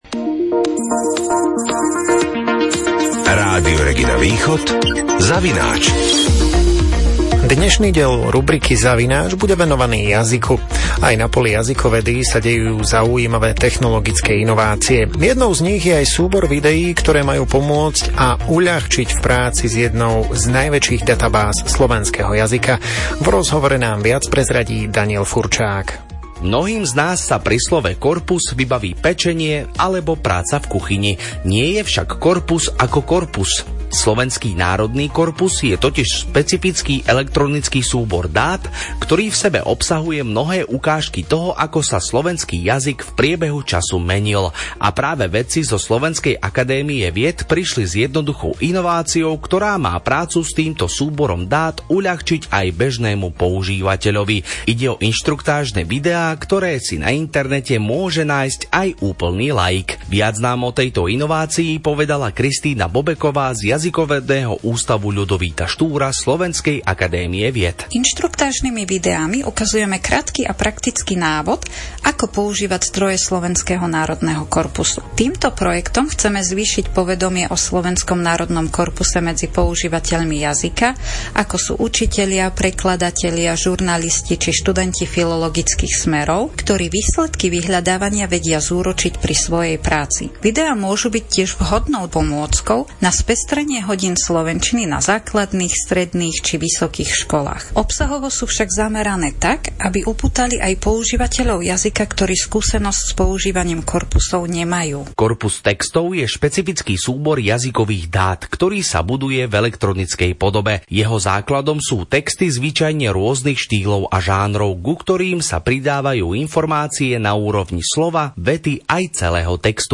Dávame vám do pozornosti reportáž Rádia Regina Východ, v ktorej sa dozviete bližšie informácie o inštruktážnych videách zameraných na prácu s databázami Slovenského národného korpusu.